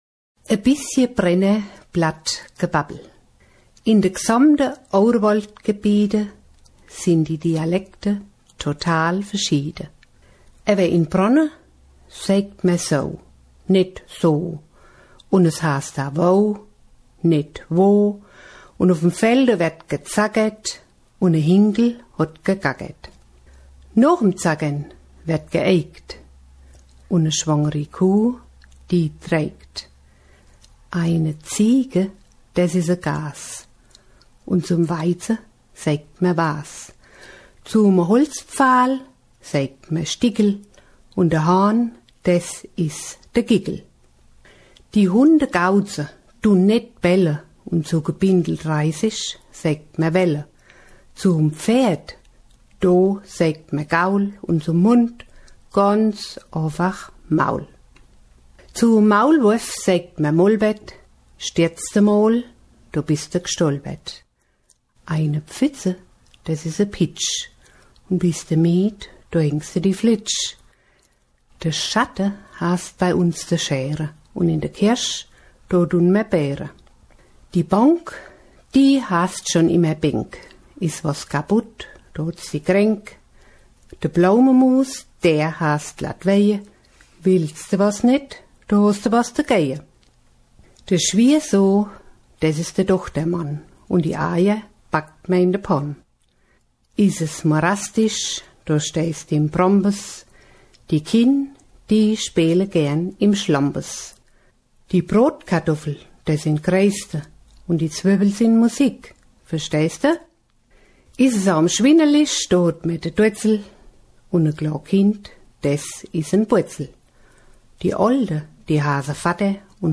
Gebabbel aus Odenwald Hessen